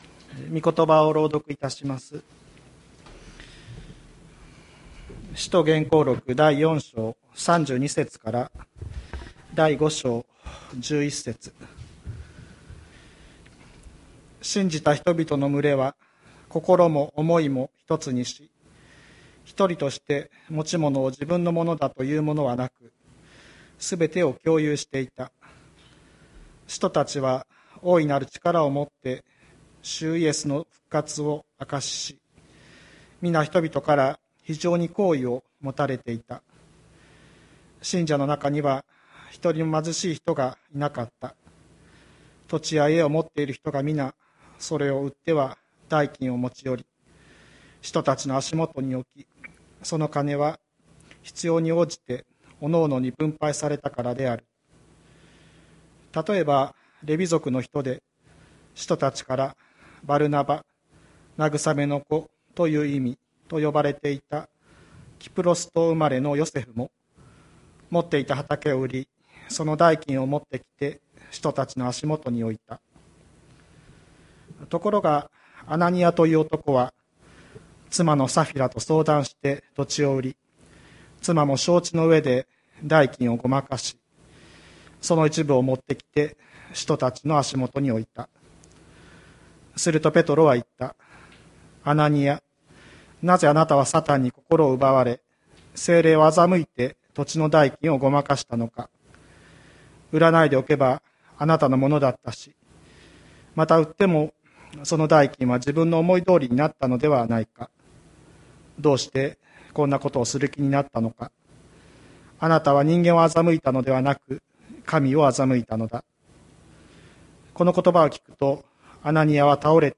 2022年01月23日朝の礼拝「献げて、戦う」吹田市千里山のキリスト教会
千里山教会 2022年01月23日の礼拝メッセージ。